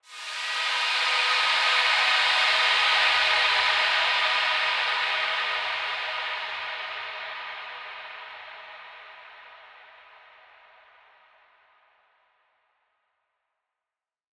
SaS_HiFilterPad08-E.wav